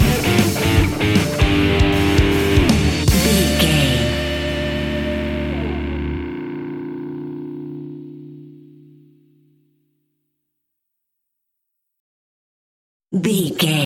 Aeolian/Minor
D
hard rock
blues rock
distortion
instrumentals
Rock Bass
Rock Drums
distorted guitars
hammond organ